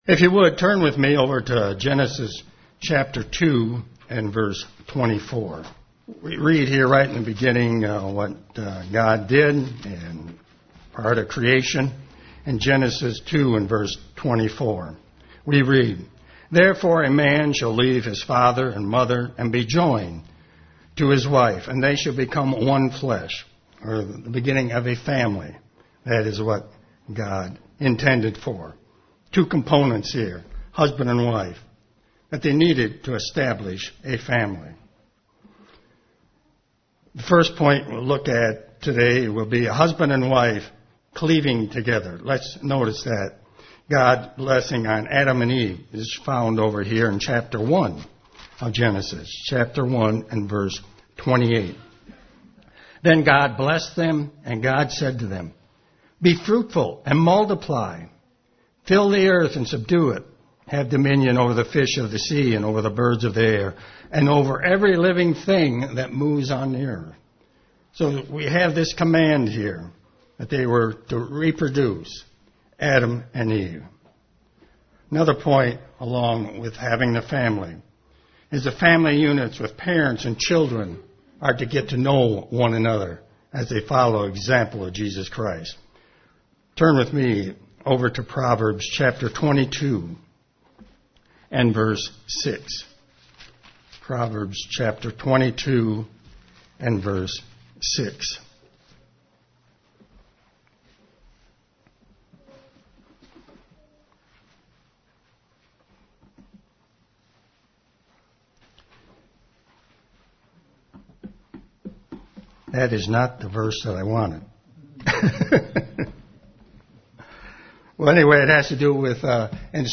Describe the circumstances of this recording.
Given in Ann Arbor, MI